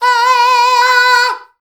AAAAAAAAAH.wav